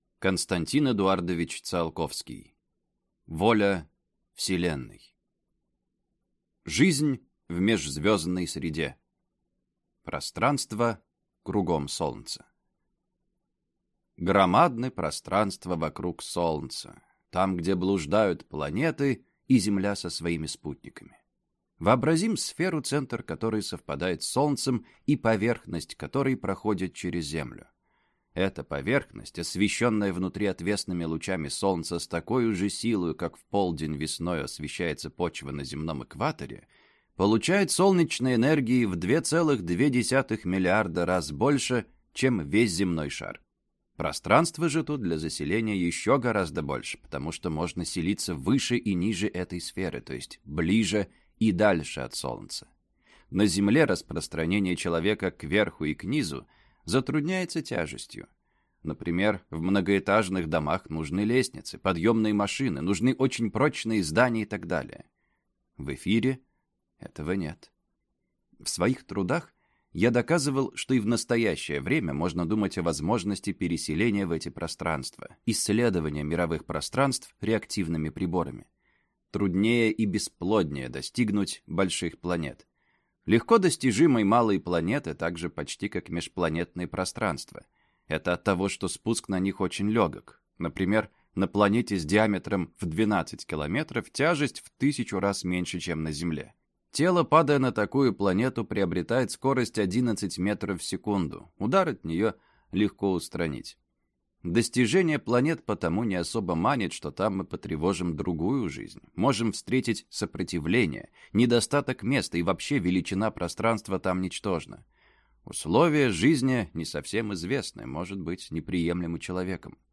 Аудиокнига Воля Вселенной | Библиотека аудиокниг